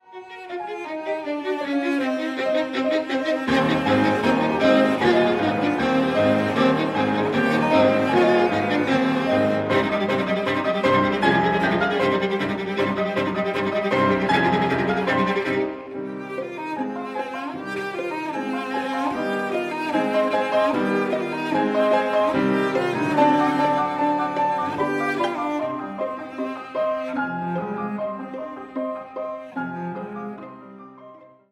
DvorakTrio.mp3